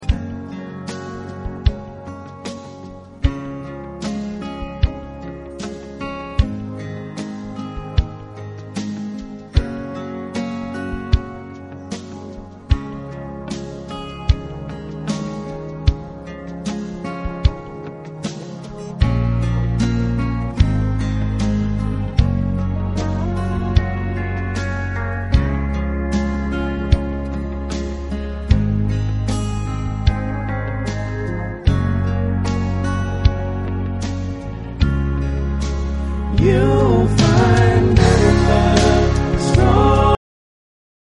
Karaoke lyrics and music will appear on your screen.